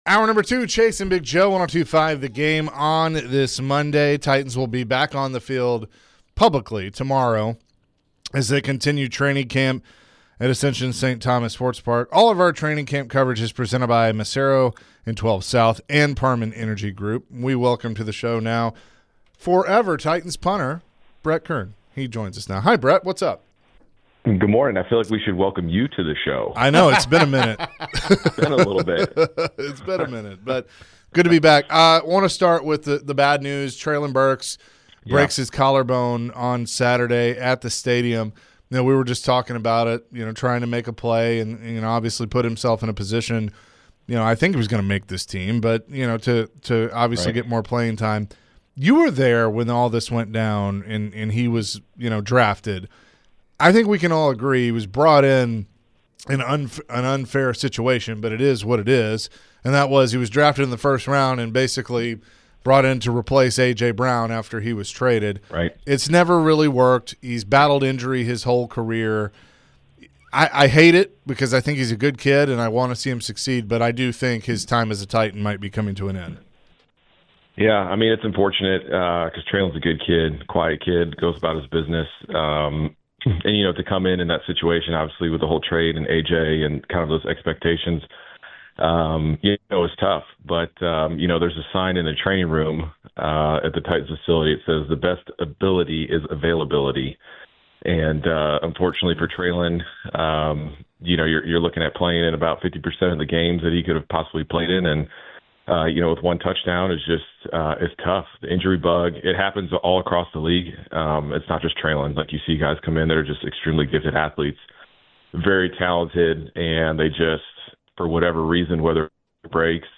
Former Titans Punter Brett Kern joined the show. Brett shared his thoughts on the Titans' first week of training camp. Brett also shared what it's like as a player to go through training camp as a player.